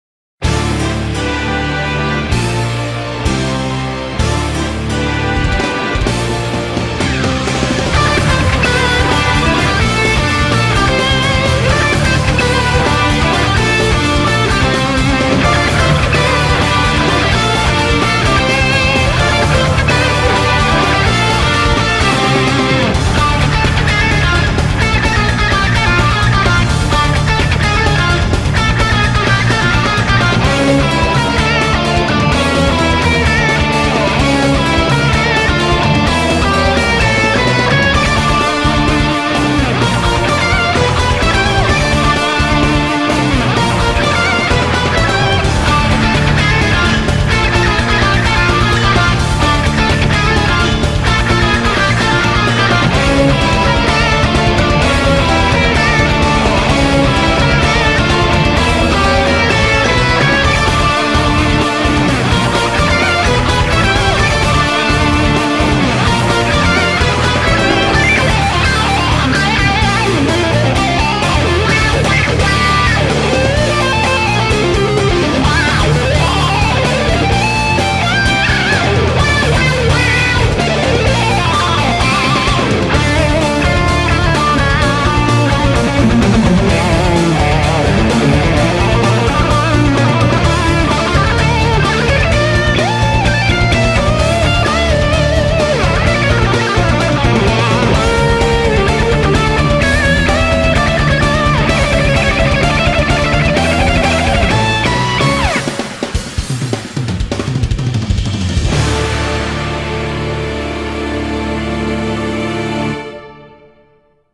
BPM128-256
Comments[PROGRESSIVE ROCK]